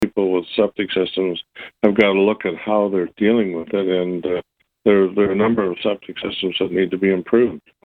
Meanwhile, mayor of Quinte West Jim Harrison says keeping Oak Lake clean is not just about agricultural runoff.
jim-harrison-1.mp3